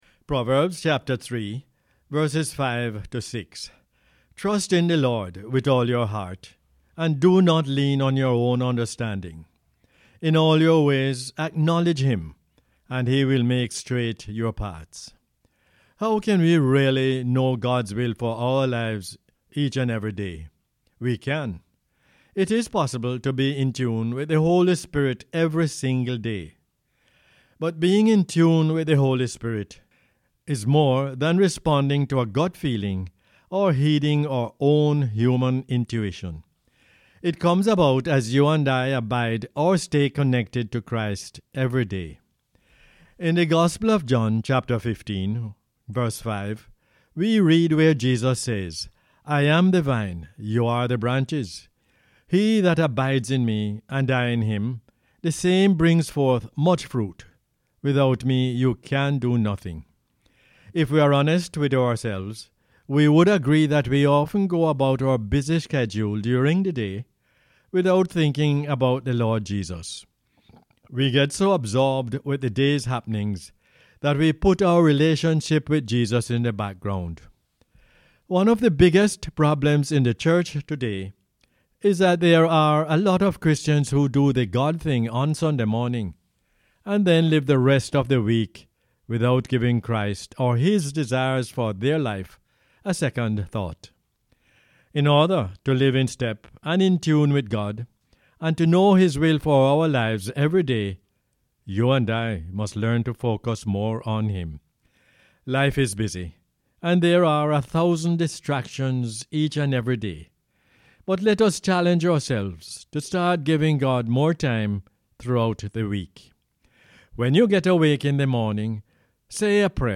Proverbs 3:5-6 is the "Word For Jamaica" as aired on the radio on 6 March 2020.